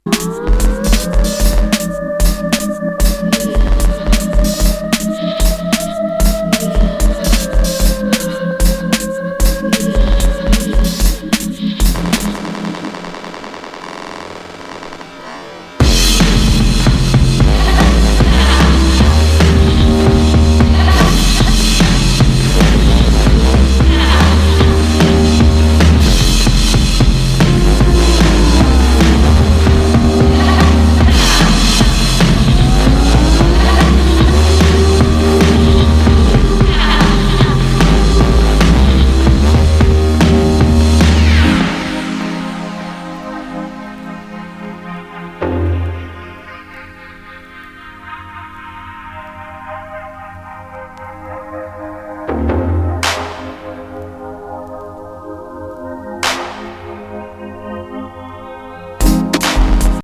Breakbeat
Scratch tools / Loops / Samples